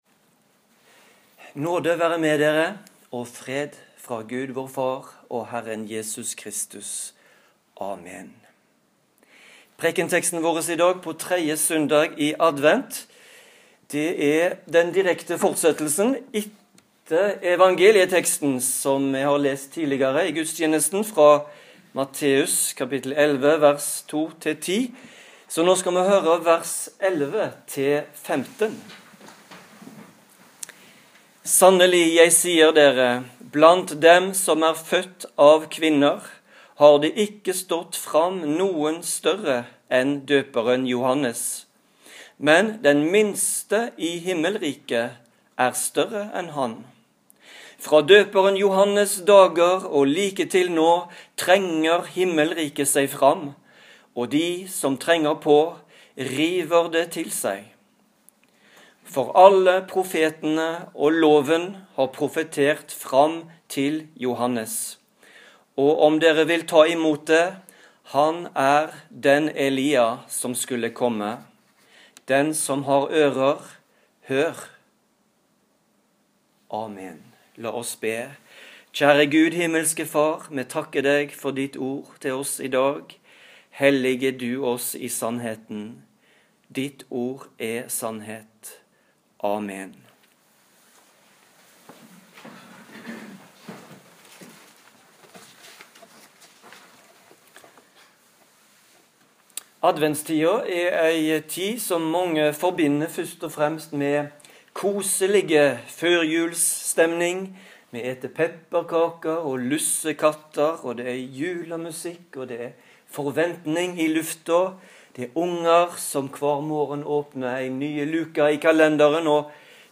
Adventpreken over Matteus 11,11-15